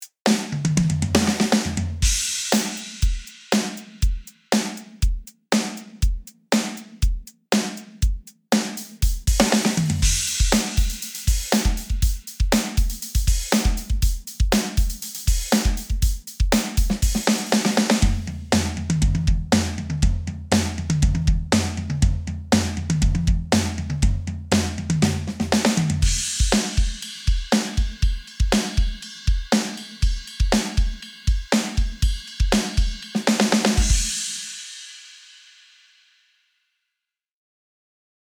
比較のため、上と同じパターンを SOFT や HEAVY にするとどうなるかも聴いてみてください。
SOFT
MTPDK_demo_soft.mp3